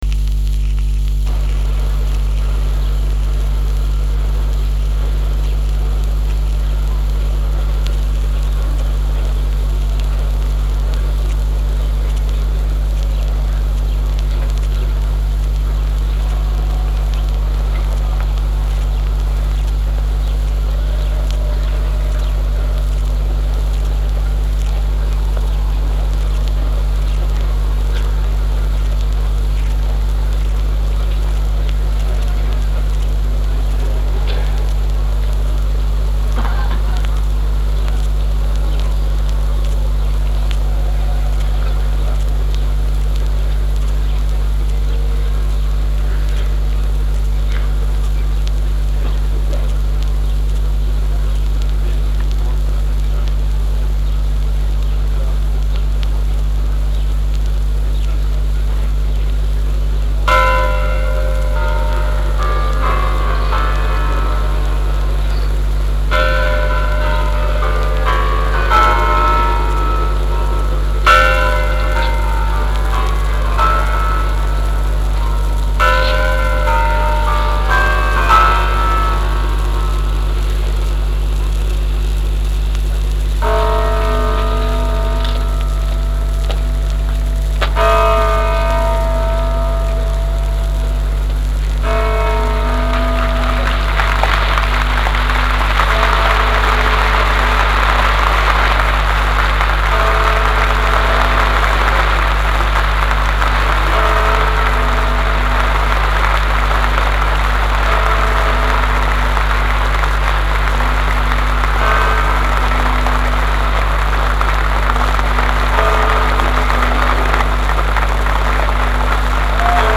Описание: Запись аудиотрансляции парада